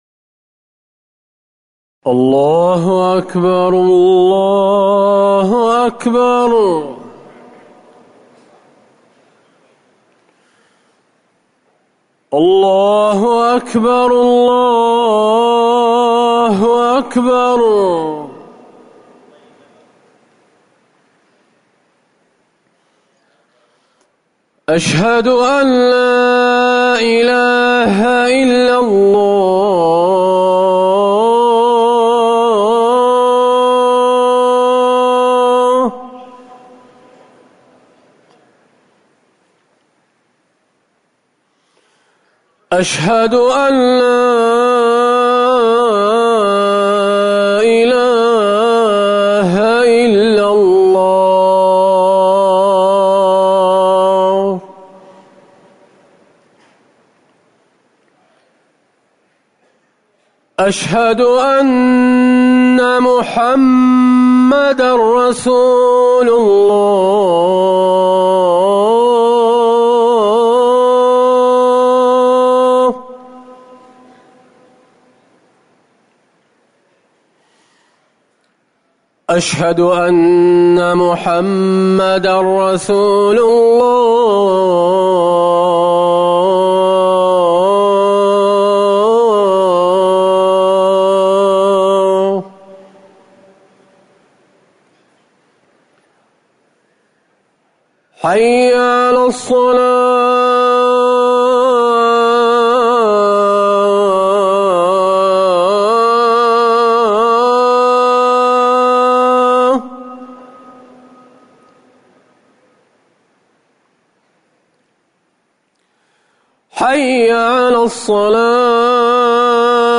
أذان الجمعة الأول - الموقع الرسمي لرئاسة الشؤون الدينية بالمسجد النبوي والمسجد الحرام
تاريخ النشر ٢١ محرم ١٤٤١ هـ المكان: المسجد النبوي الشيخ